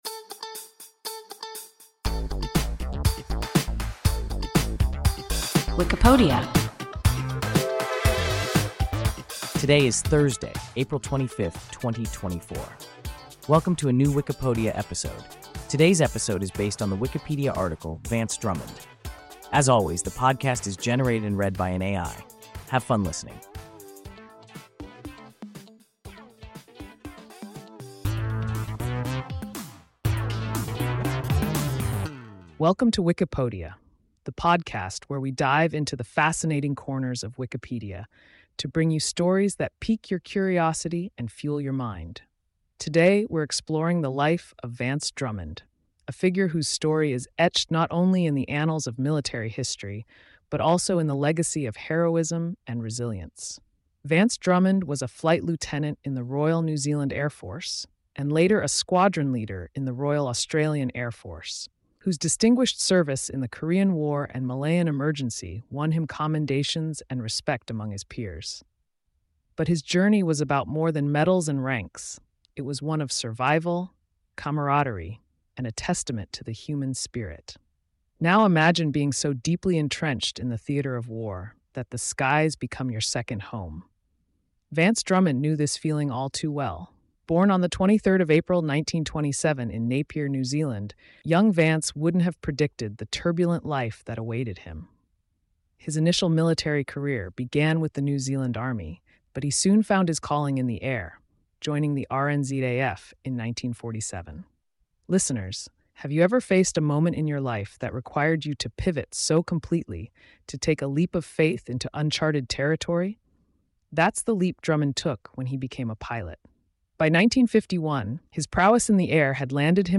Vance Drummond – WIKIPODIA – ein KI Podcast
Wikipodia – an AI podcast